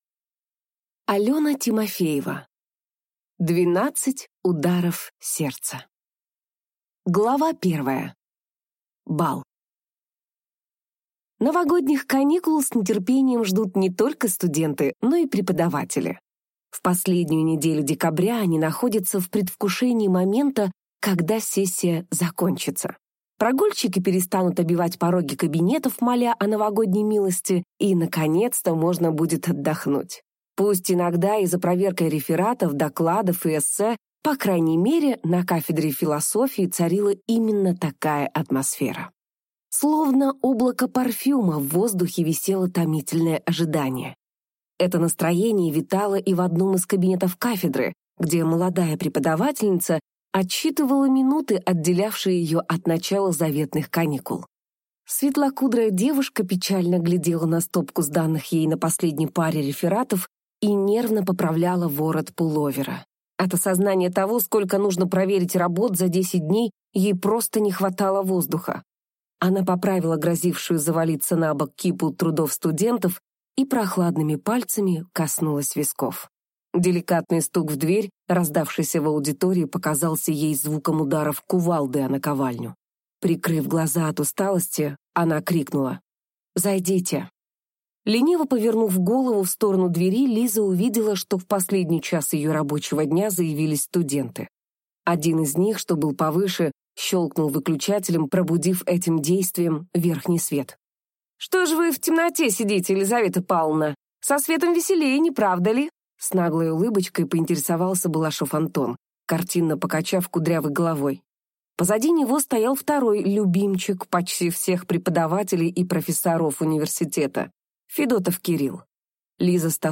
Аудиокнига 12 ударов сердца | Библиотека аудиокниг